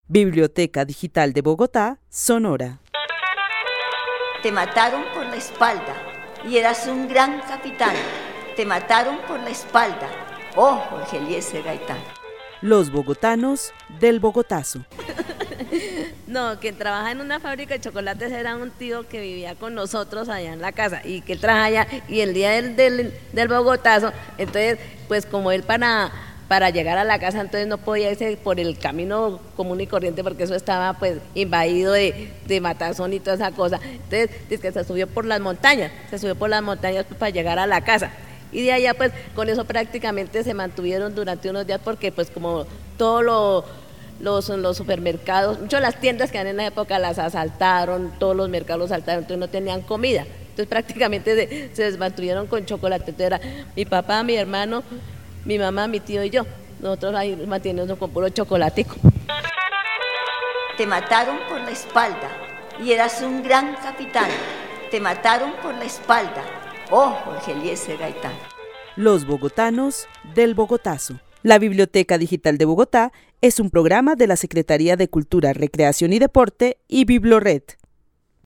Narración oral de los hechos sucedidos en Bogotá el 9 de abril de 1948.
También menciona cómo funcionaban los servicios públicos de electricidad y agua en esa época. El testimonio fue grabado en el marco de la actividad "Los bogotanos del Bogotazo" con el club de adultos mayores de la Biblioteca Carlos E. Restrepo.